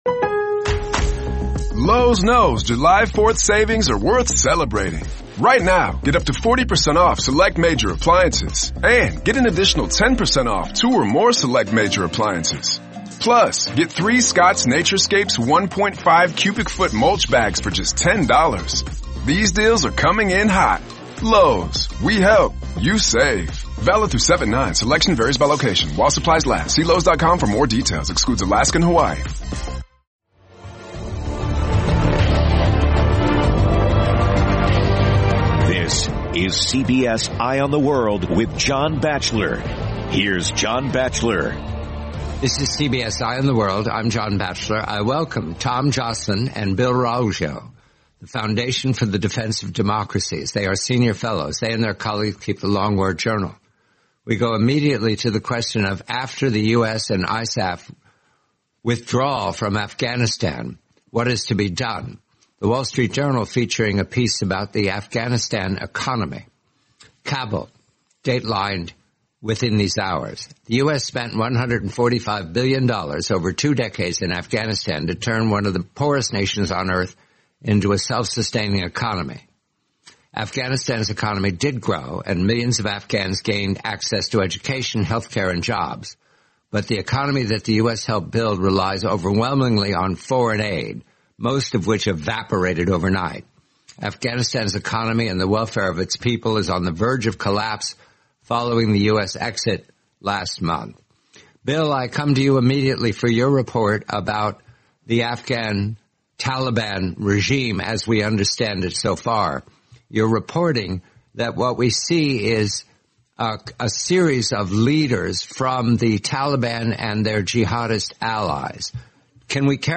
forty-minute interview